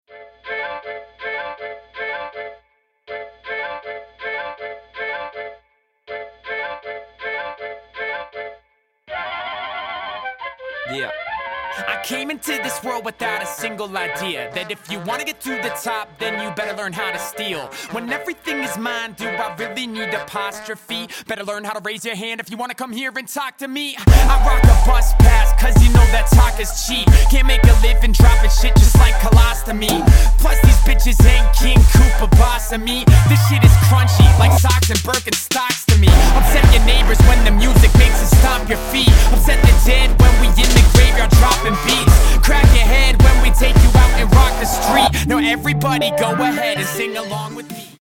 • Качество: 160, Stereo
Хип-хоп
Rap